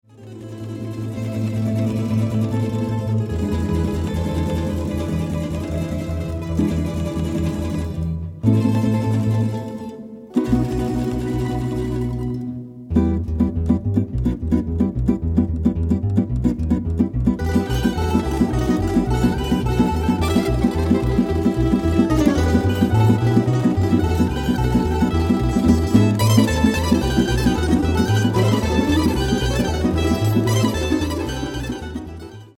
South Slavic American Musical Traditions
virtuoso playing of the prim tamburitza
prim, brac and celo
bugarija and vocals